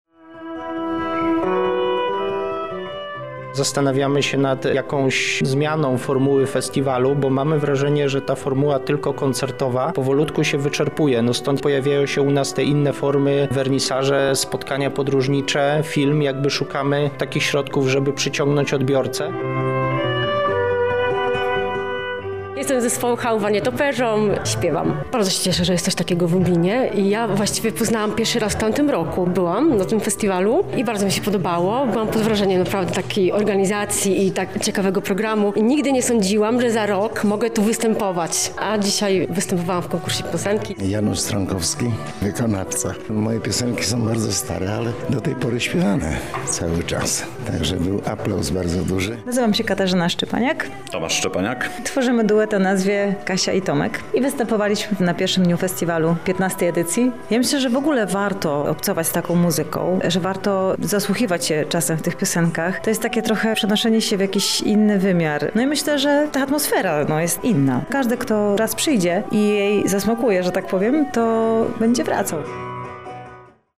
RELACJA
Strojne-w-biel-relacja-1.mp3